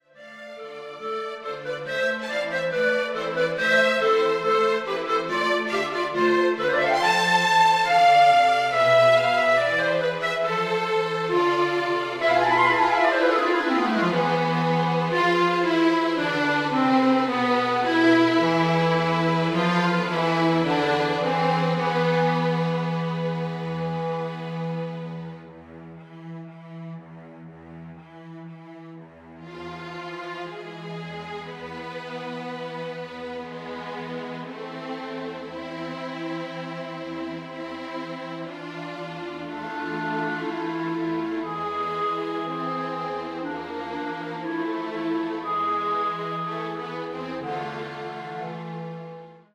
expressive, nachdenkliche und temperamentvolle Töne